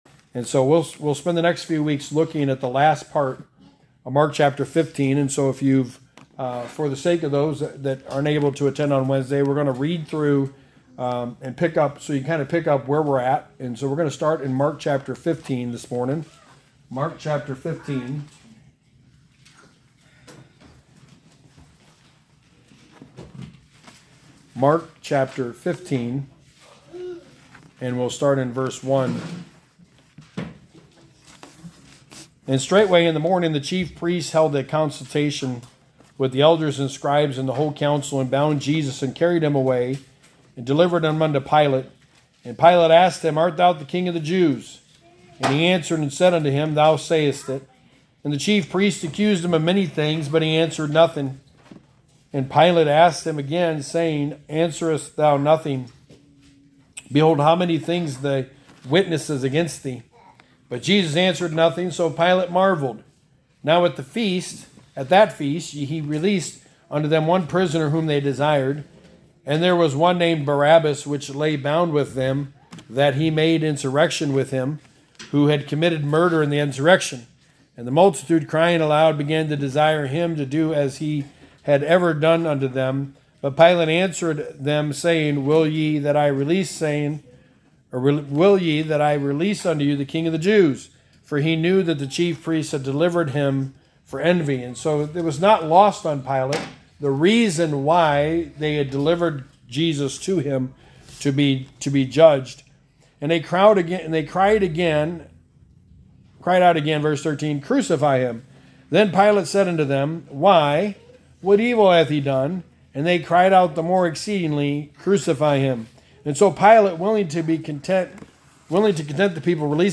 Service Type: Sunday Morning Study of the book of Mark